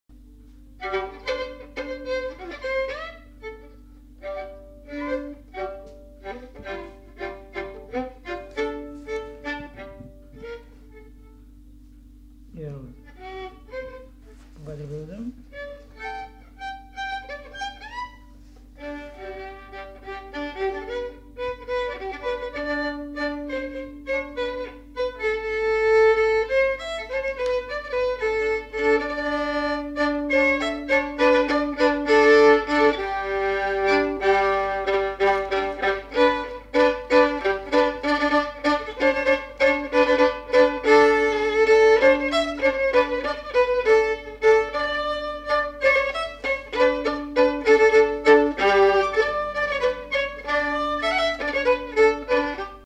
Aire culturelle : Lomagne
Lieu : Garganvillar
Genre : morceau instrumental
Instrument de musique : violon
Danse : valse